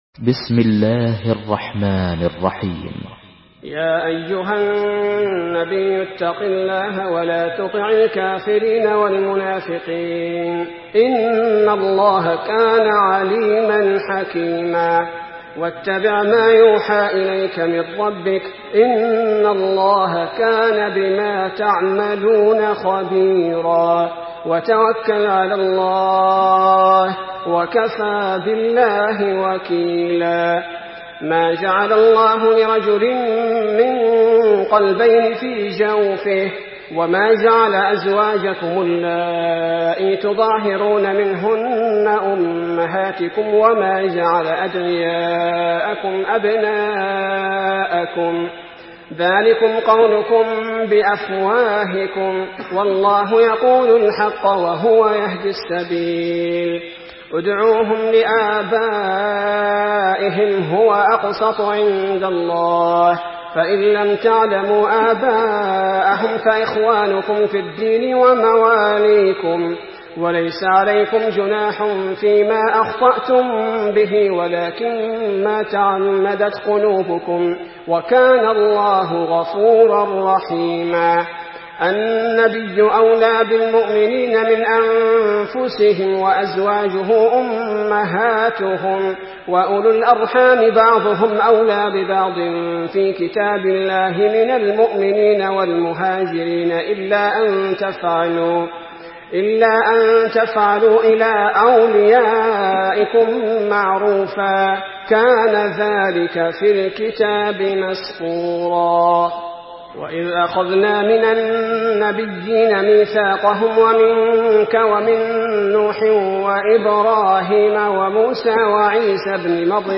Surah Ahzab MP3 by Abdul bari al thubaity in Hafs An Asim narration.
Murattal Hafs An Asim